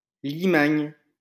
The Limagne (French pronunciation: [limaɲ]